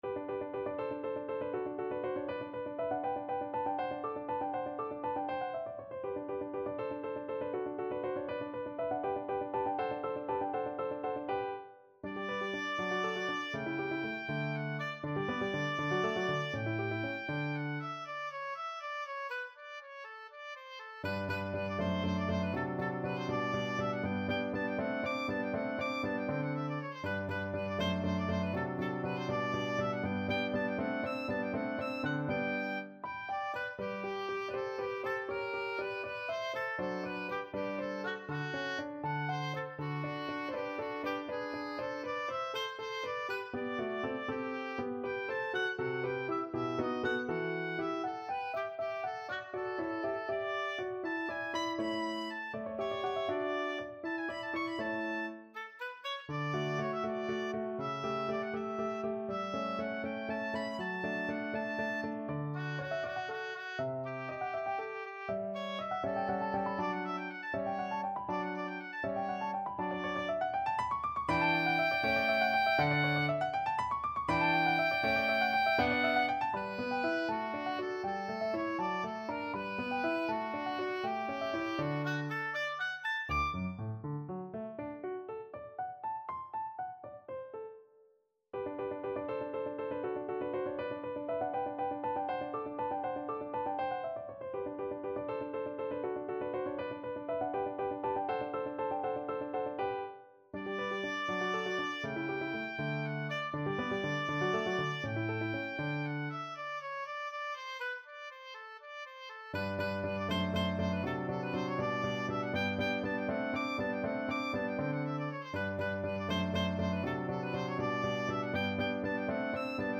Oboe
6/8 (View more 6/8 Music)
G major (Sounding Pitch) (View more G major Music for Oboe )
~ = 100 Allegro (View more music marked Allegro)
Classical (View more Classical Oboe Music)